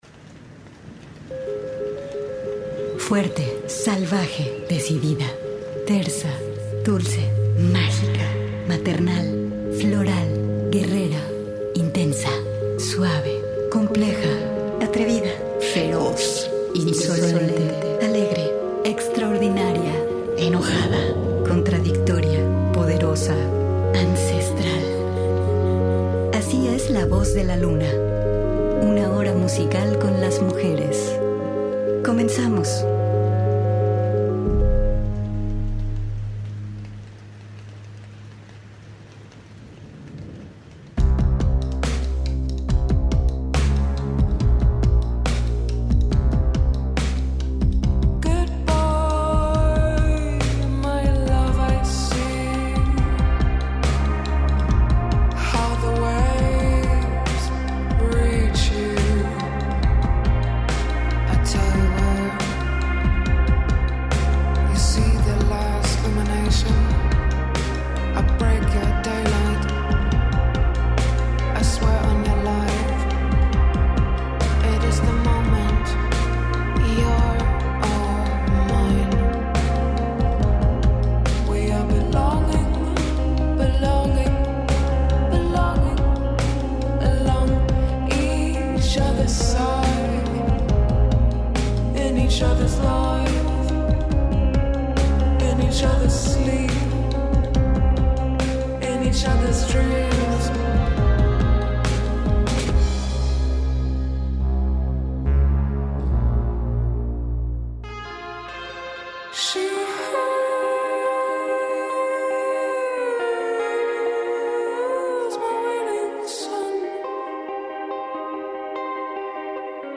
Hoy te traemos música nueva para ti.